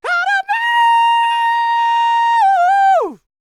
DD FALSET014.wav